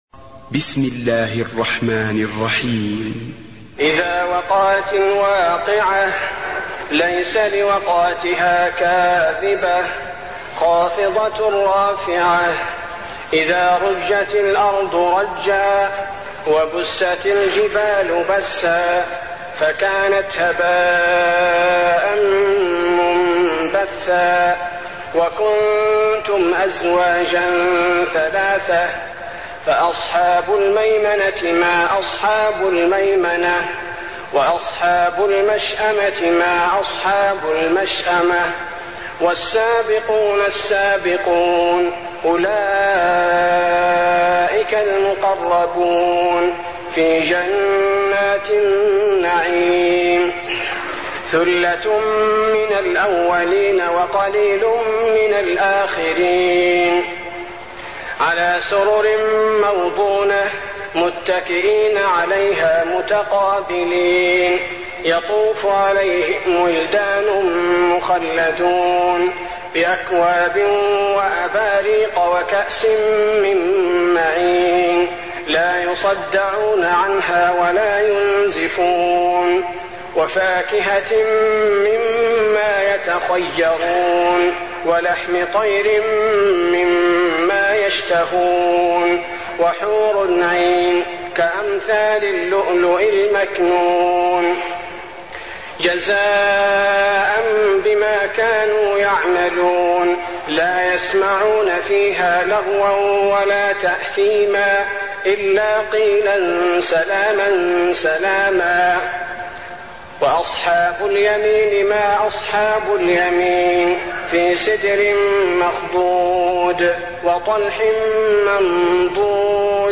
المكان: المسجد النبوي الواقعة